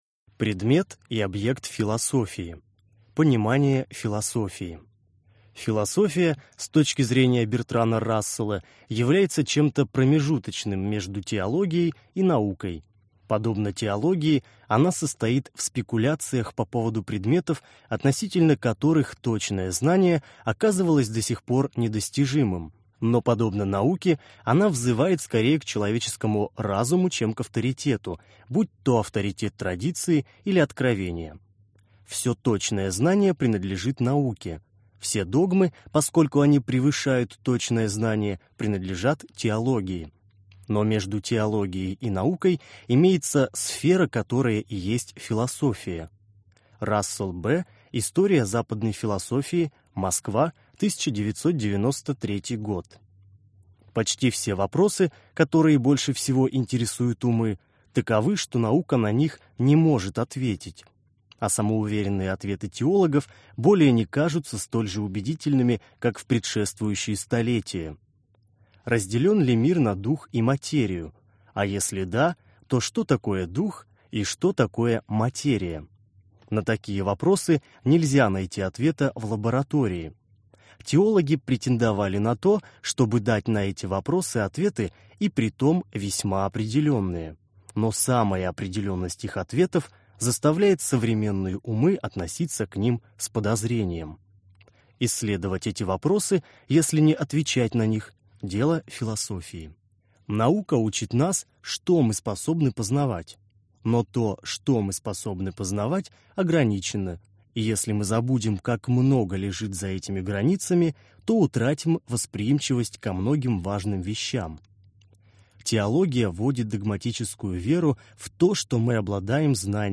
Аудиокнига Философия. Курс лекций | Библиотека аудиокниг